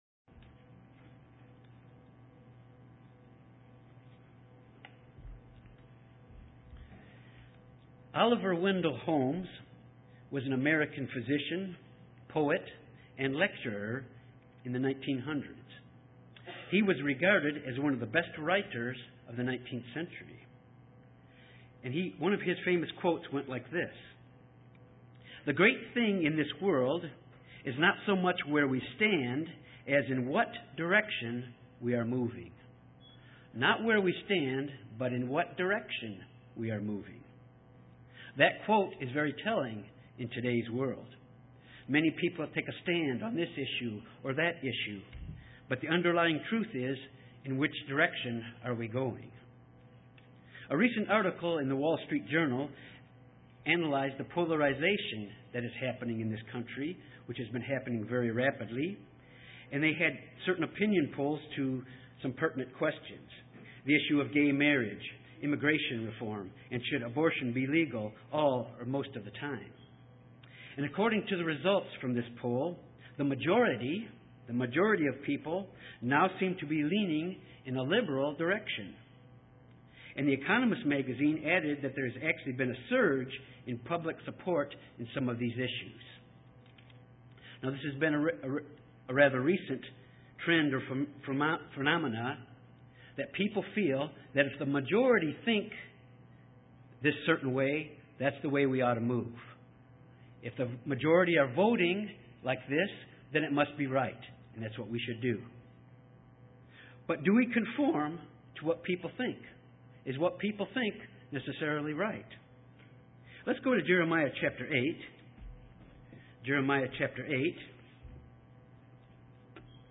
Given in Little Rock, AR
It’s a way of life and it comes from our relationship with God UCG Sermon Studying the bible?